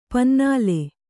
♪ pannāle